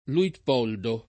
[ luitp 0 ldo ]